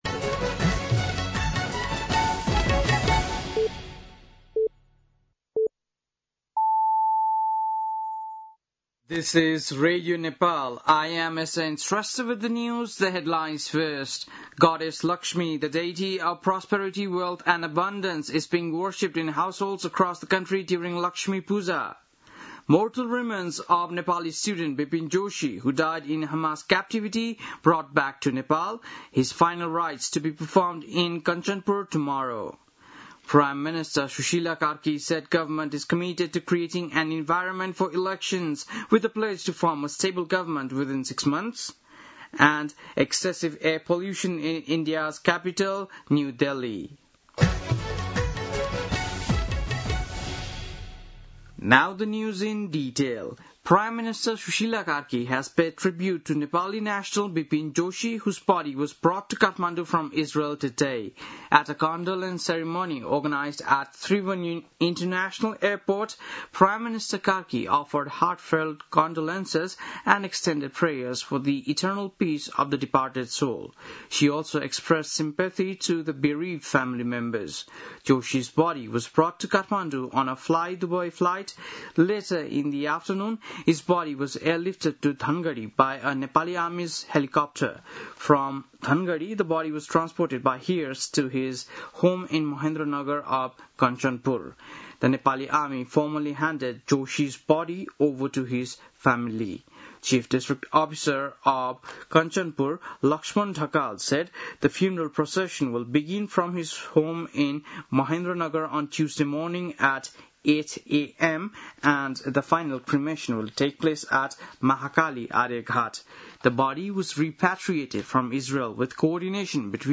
बेलुकी ८ बजेको अङ्ग्रेजी समाचार : ३ कार्तिक , २०८२
8-pm-english-news-7-03.mp3